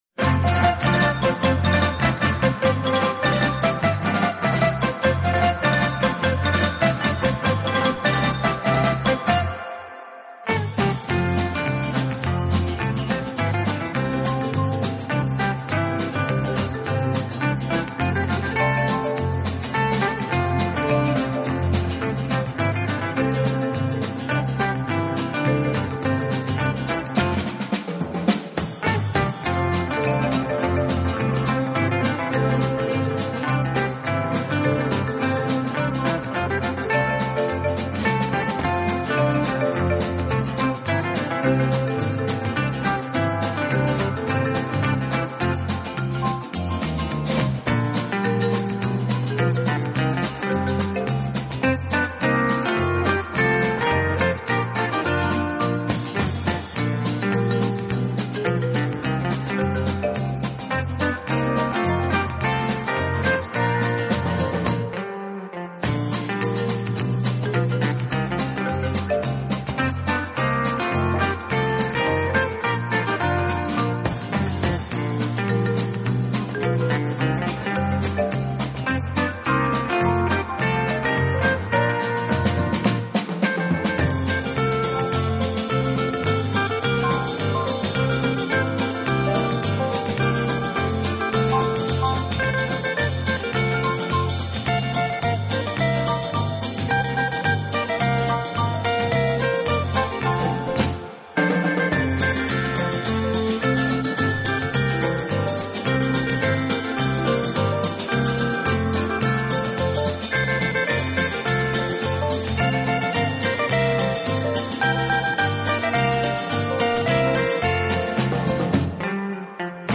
【顶级轻音乐】
以热烈的旋律，独特的和声赢得千百万听众